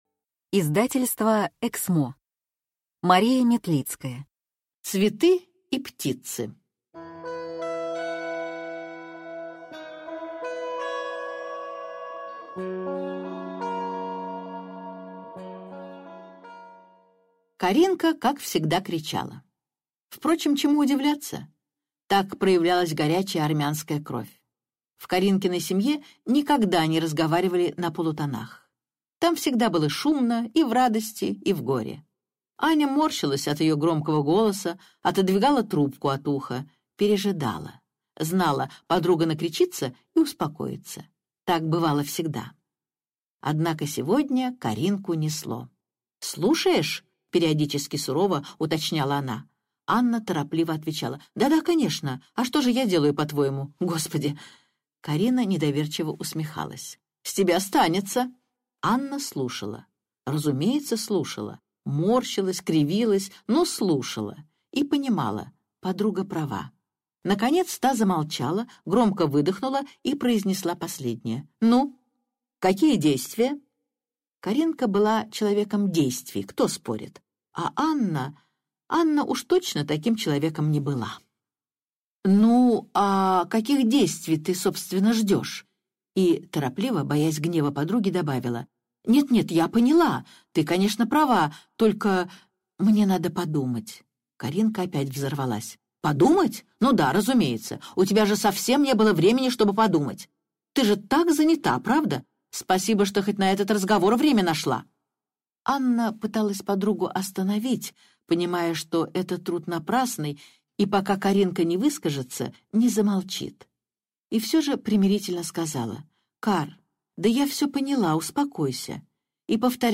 Цветы и птицы (слушать аудиокнигу бесплатно) - автор Мария Метлицкая